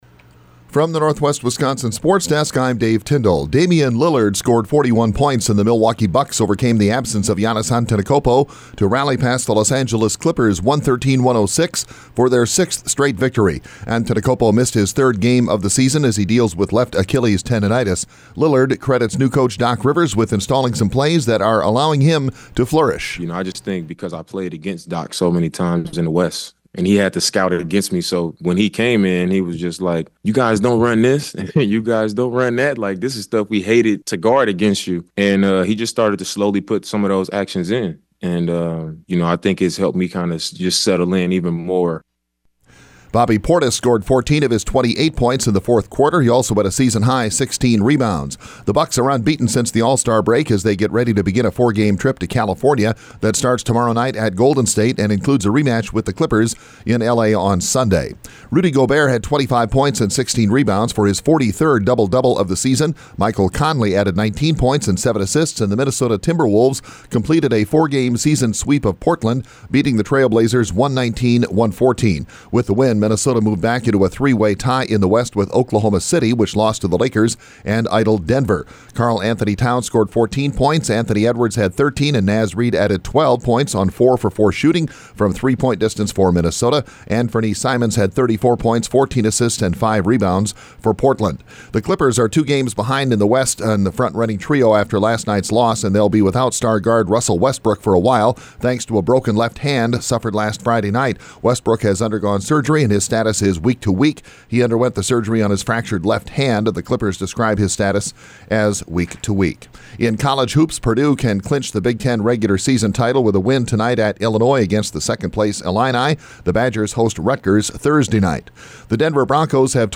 Today’s sportscast from the Northwest Wisconsin Sports Desk.